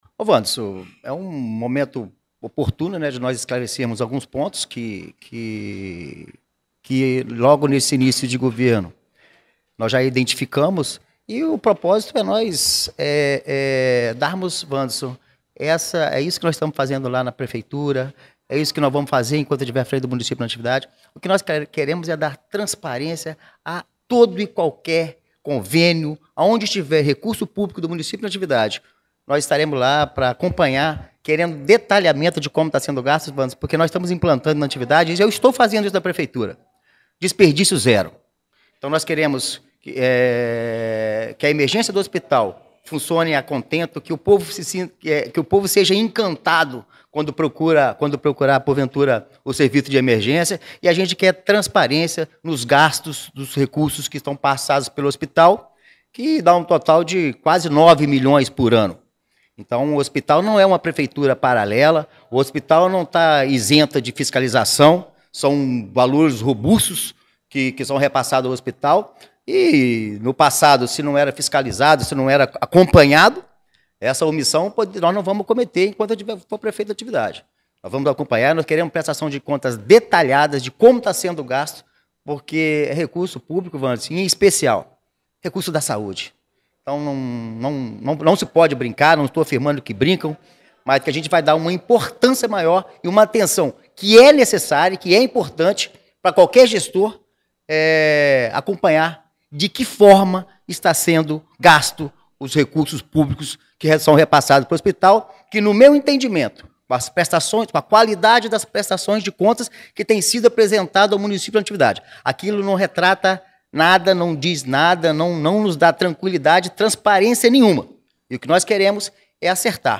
7 de julho de 2025 ENTREVISTAS, NATIVIDADE AGORA